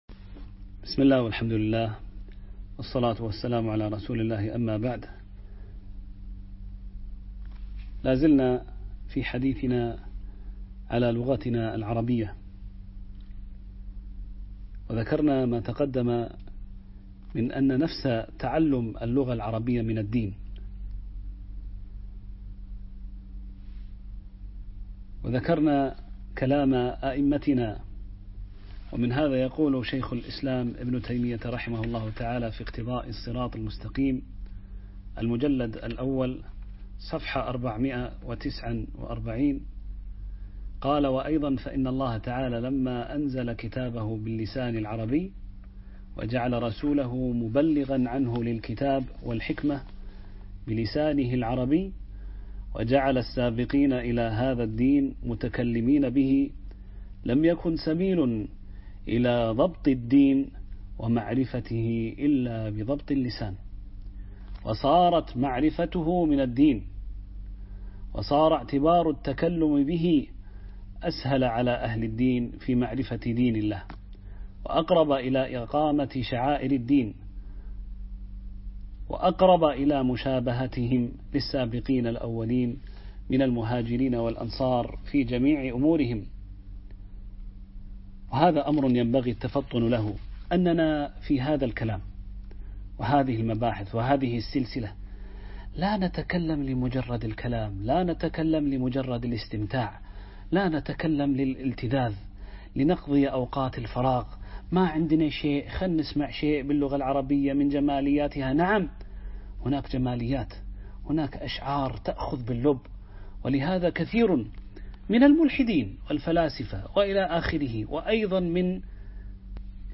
بحوث في لغتنا العربية - الدرس الثالث